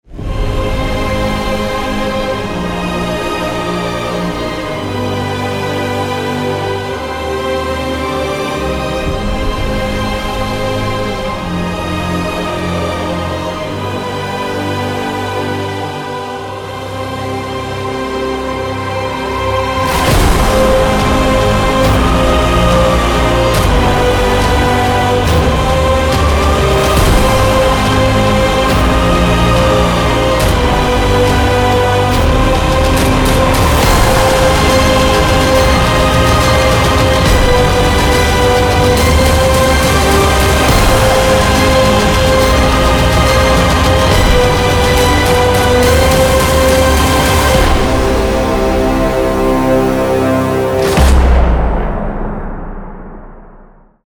• Качество: 320, Stereo
громкие
красивые
инструментальные
оркестр
классические